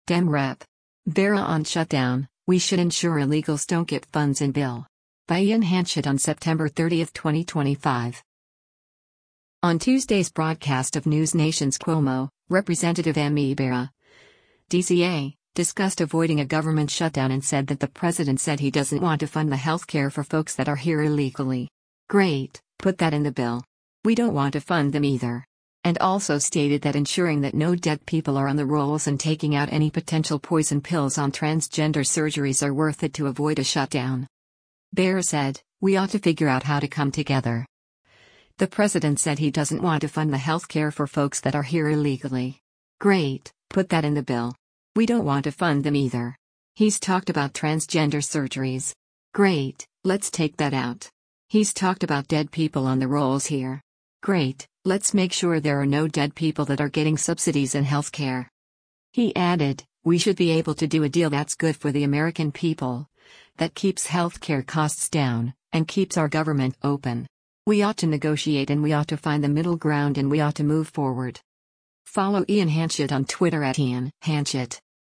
On Tuesday’s broadcast of NewsNation’s “Cuomo,” Rep. Ami Bera (D-CA) discussed avoiding a government shutdown and said that “The president said he doesn’t want to fund the health care for folks that are here illegally. Great, put that in the bill. We don’t want to fund them either.” And also stated that ensuring that no dead people are on the rolls and taking out any potential poison pills on transgender surgeries are worth it to avoid a shutdown.